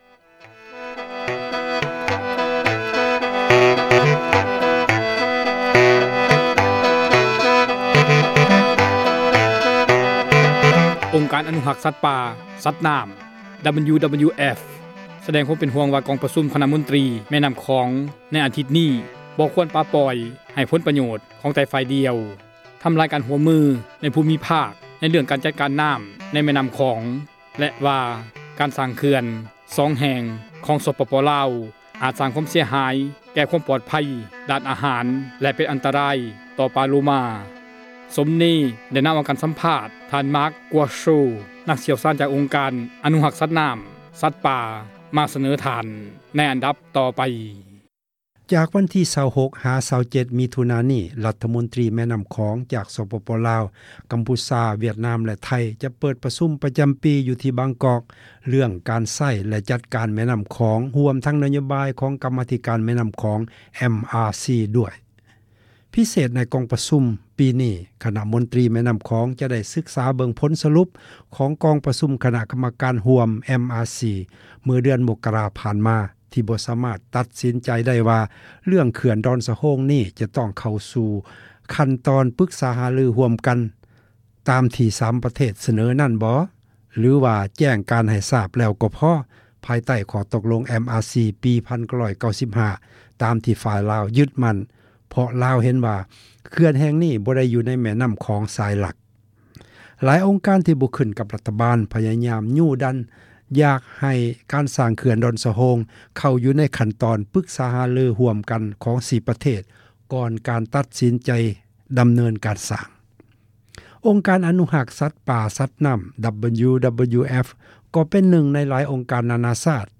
ສັມພາດອົງການອະນຸຣັກ ສັຕປ່າ ສັດນໍ້າ